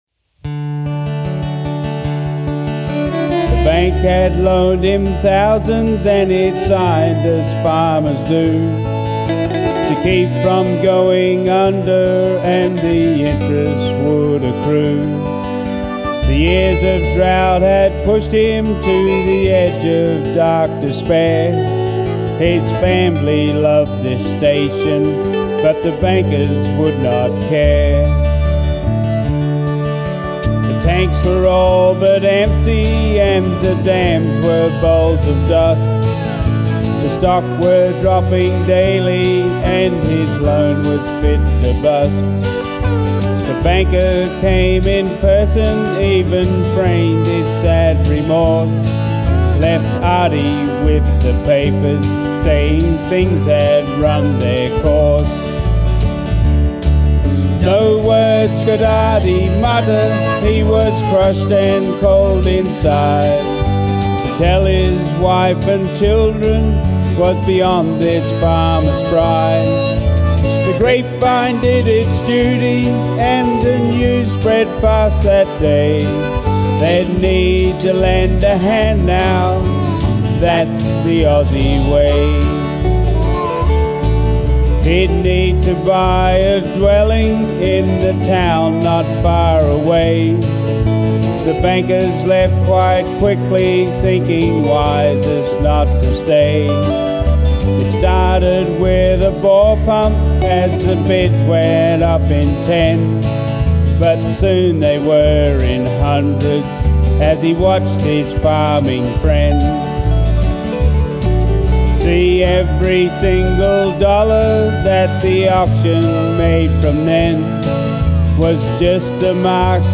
bush balladeer